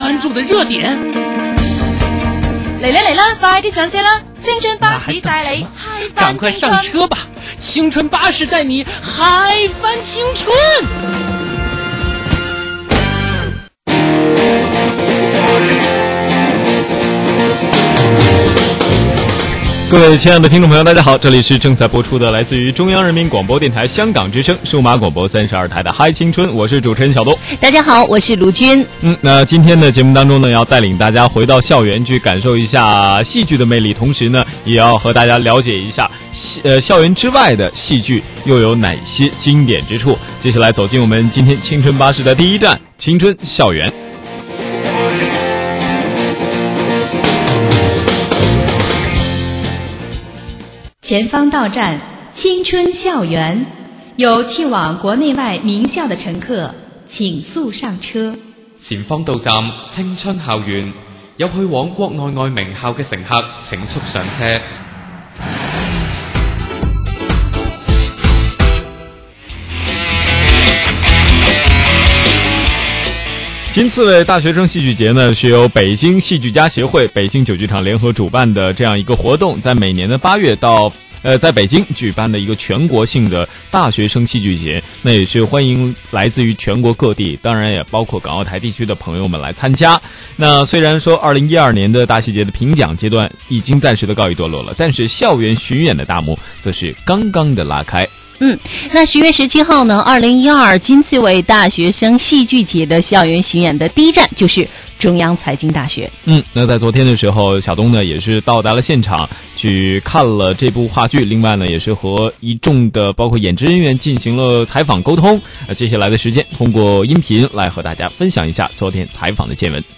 2012-10-30中央人民广播电台对话剧《村官之路》采访录音.wma